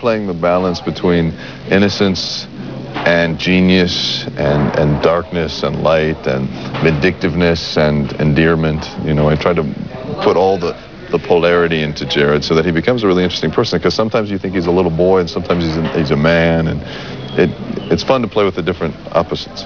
Interview de Michael T. Weiss en V.O ( 214 Ko )
Interview de Michael T. Weiss lors de son passage à Paris